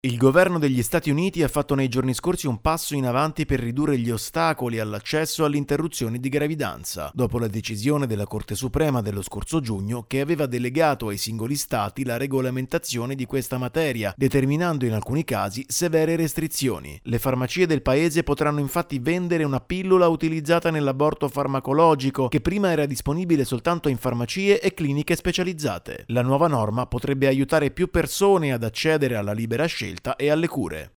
A più di un anno dall’inizio del conflitto nel Tigray, oltre nove milioni di persone hanno bisogno di assistenza. Il servizio